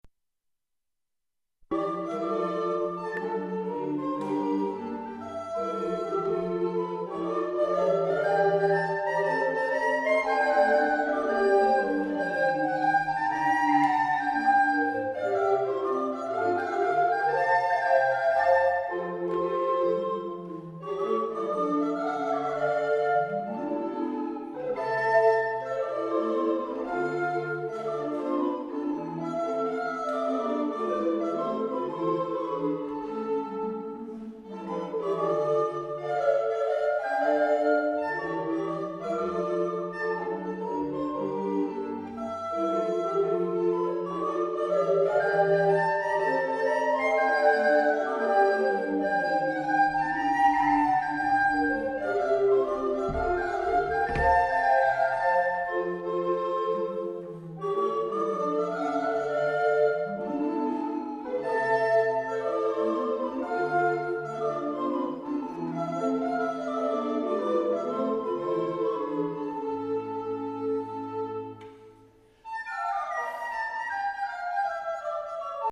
Ungdomsblokkfløytistene Uranienborg på Italia-turné.
Fra en konsert i Santa Flora e Lucilla i Torre di Siena 11. juli 2006.